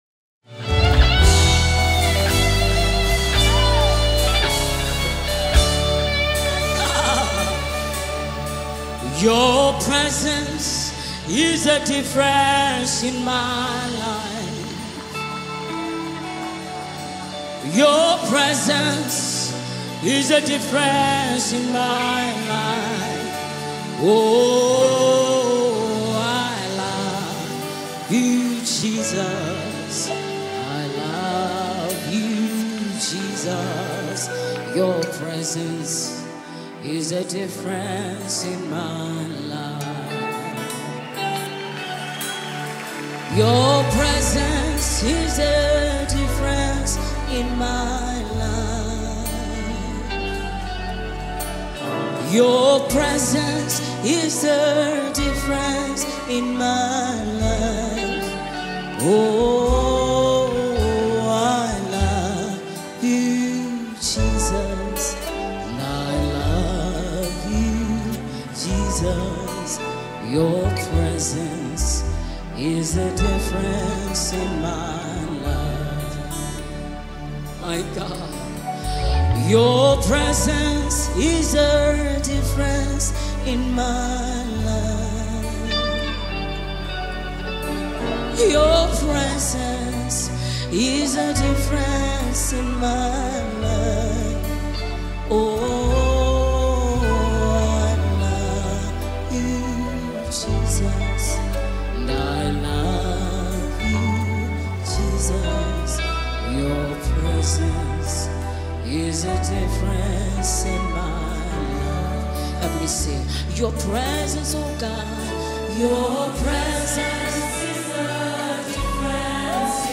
The renowned worship leader
a mesmerising melody